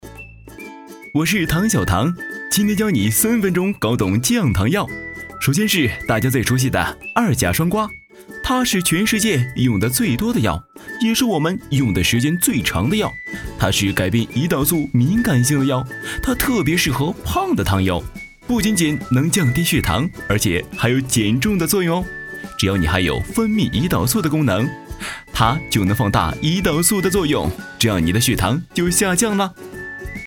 飞碟说-男48-汤药.mp3